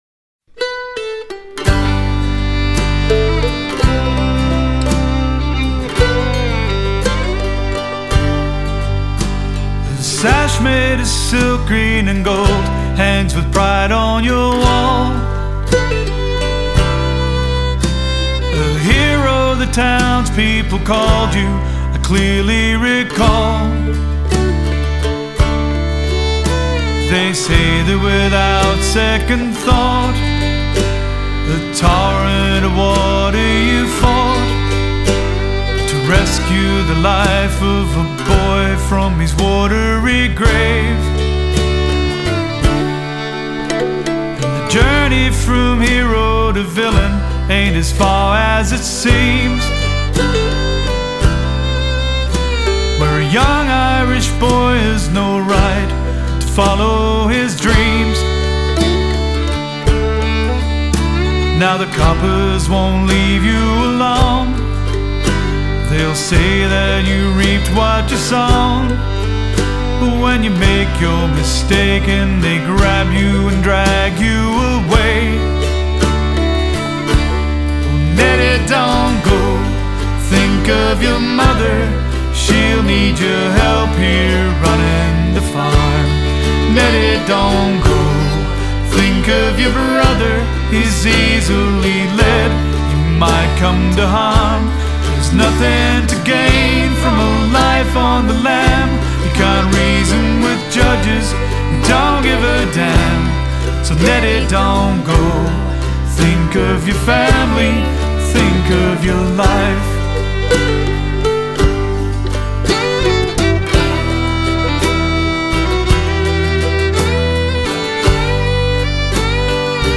songwriter and singer sharing stories